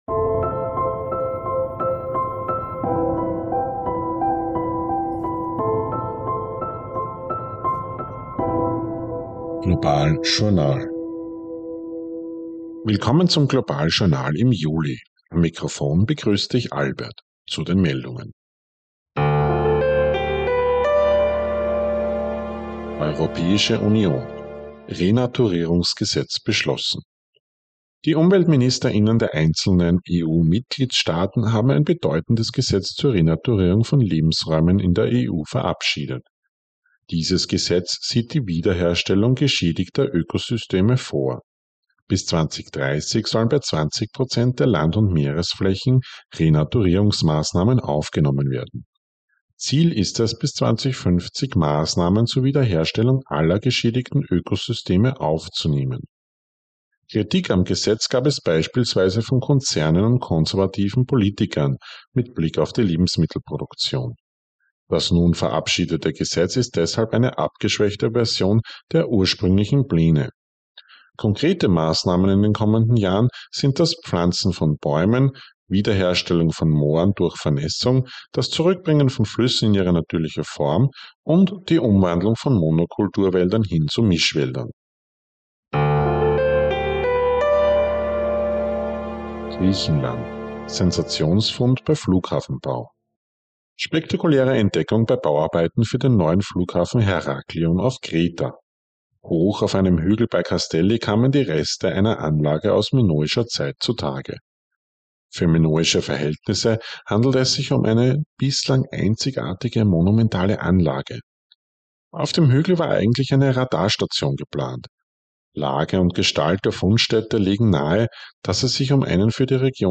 News Update Juli 2024